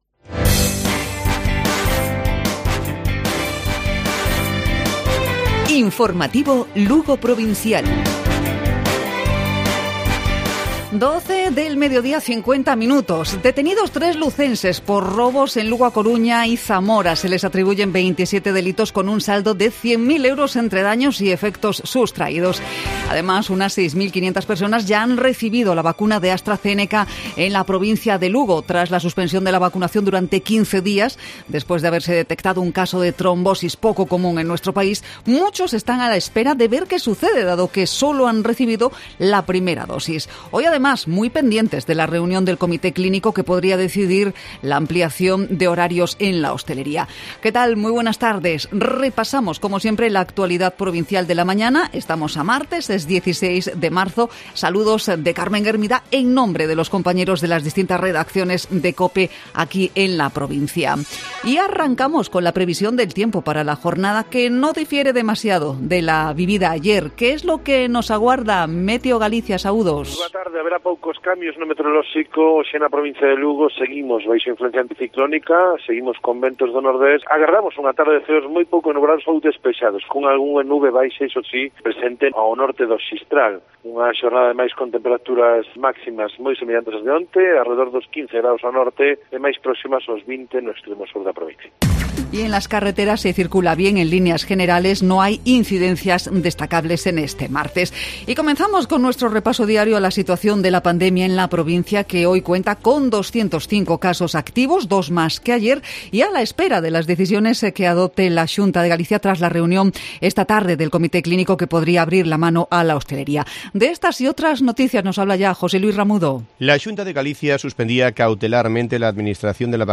Informativo Provincial Cope Lugo.